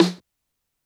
Bhasker 808 SNR 2.wav